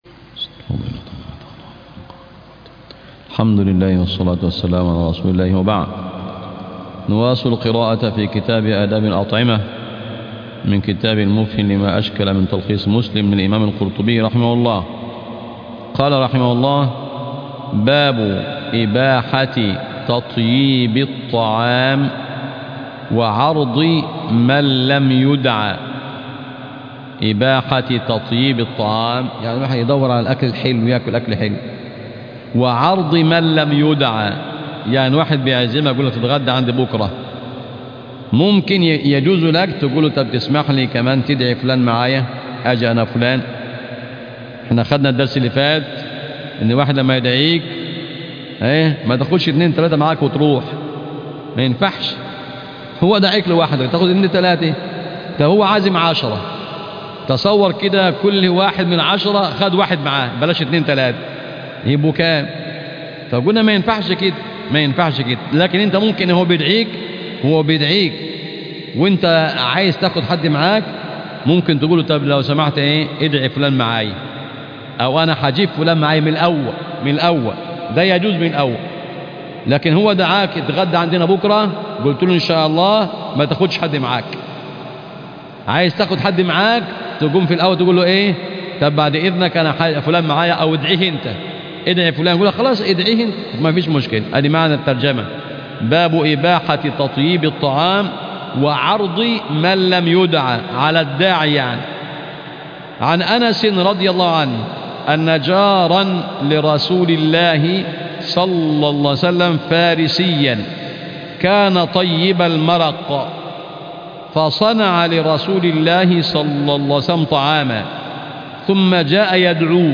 الدرس 4 من كتاب أداب الاطعمة